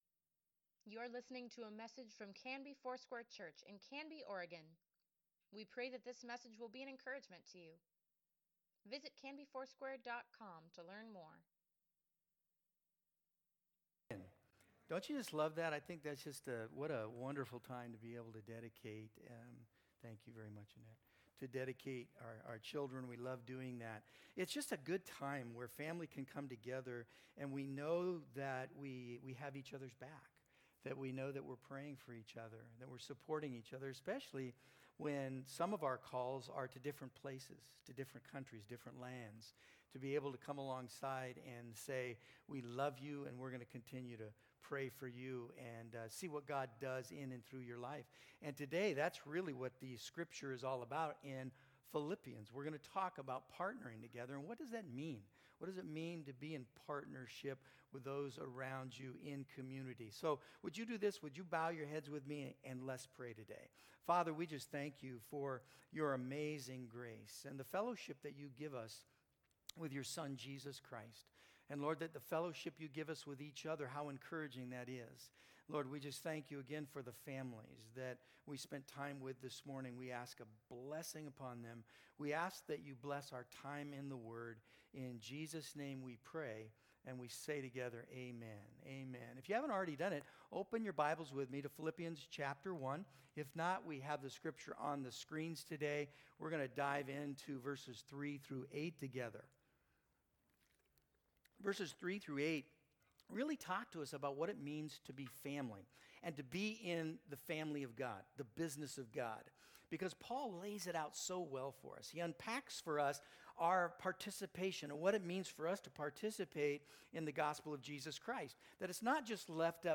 Weekly Email Water Baptism Prayer Events Sermons Give Care for Carus CONFIDENT: Philippians 1:3-8 September 19, 2021